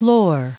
Prononciation du mot lore en anglais (fichier audio)
Prononciation du mot : lore